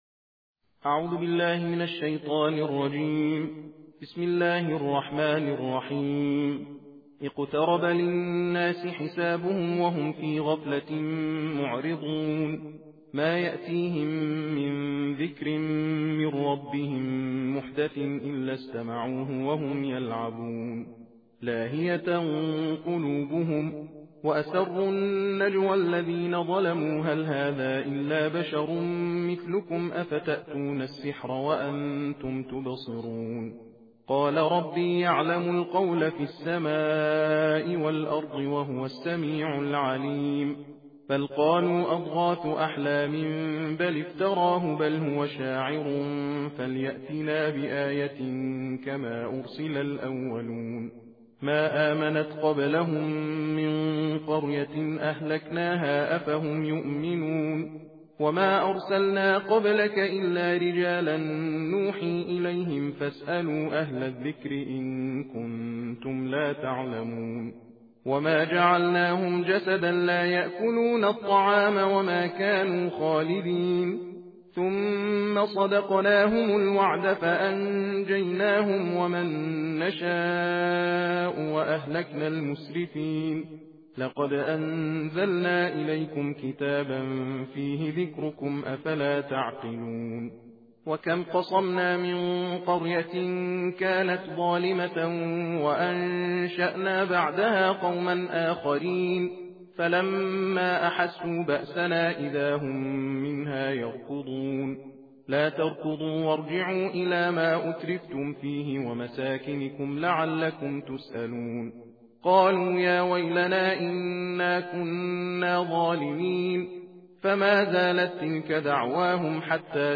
صوت/ تندخوانی جزء هفدهم قرآن کریم